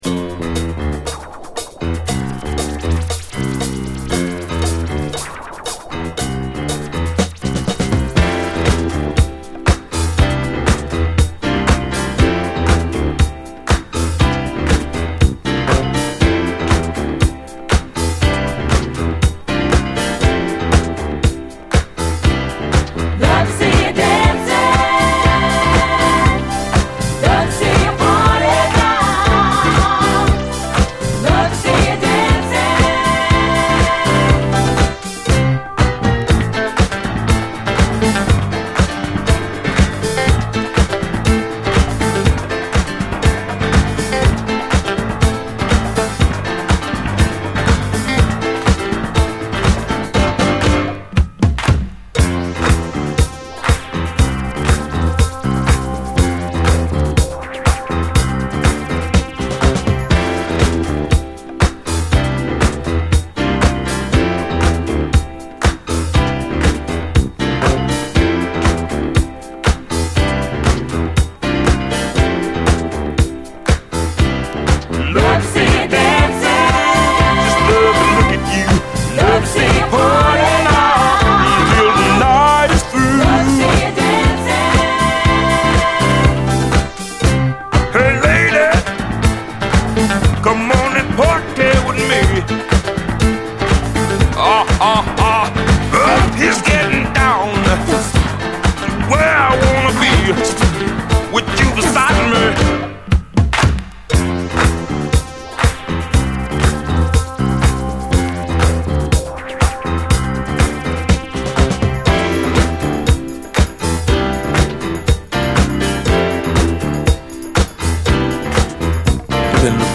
ファンキー・ロック
鬼太いベース・ラインがヤバいファンク